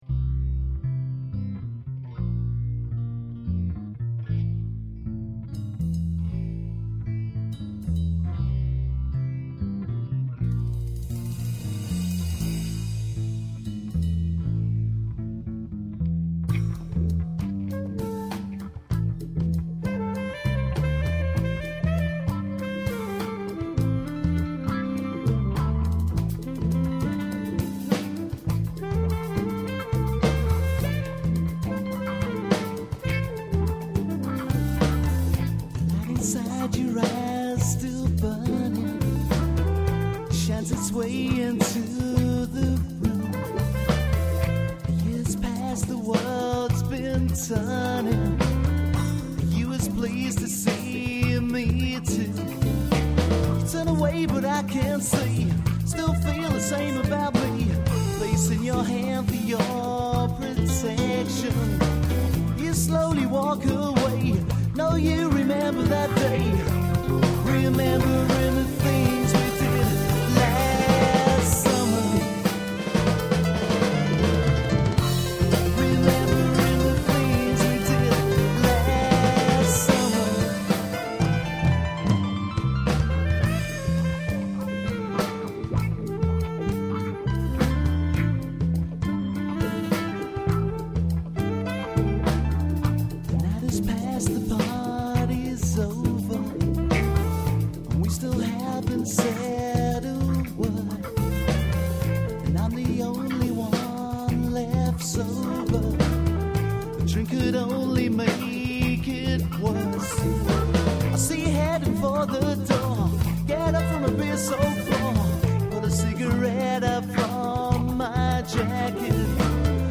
vocals & guitar
lead guitar
bass guitar
keyboards
drums
Saxophone